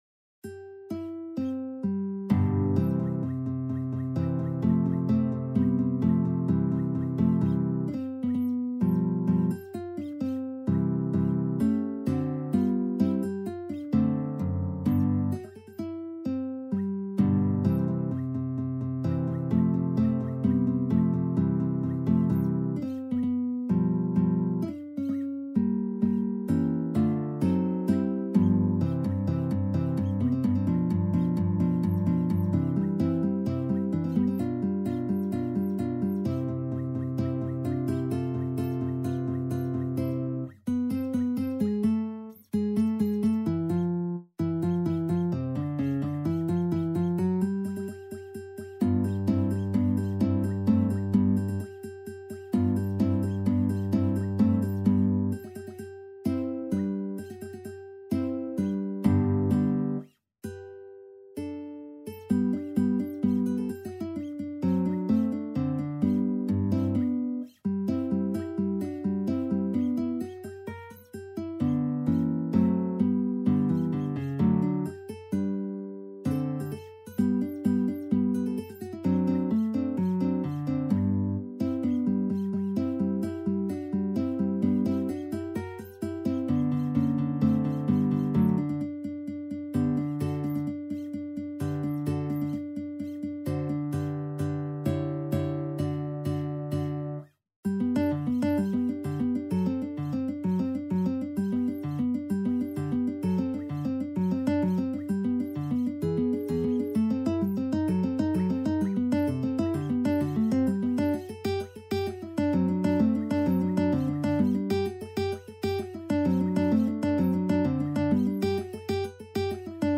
Guitar  (View more Advanced Guitar Music)
Classical (View more Classical Guitar Music)